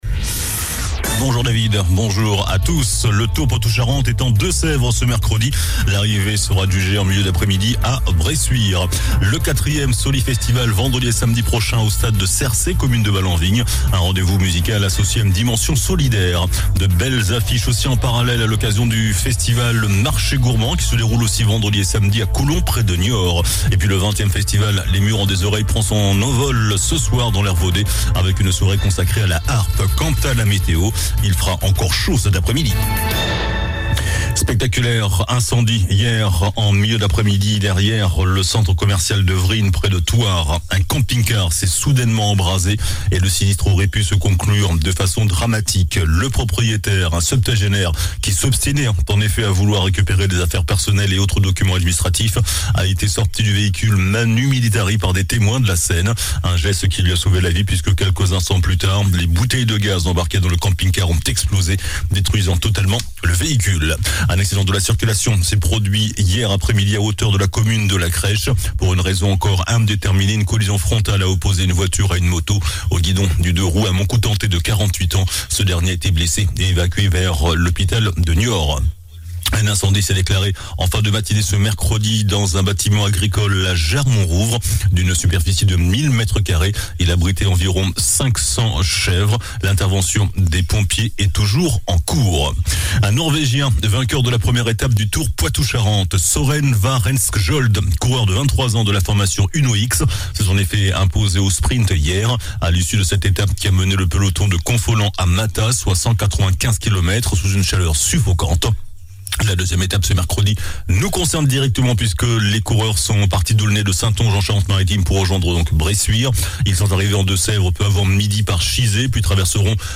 JOURNAL DU MERCREDI 23 AOÛT ( MIDI )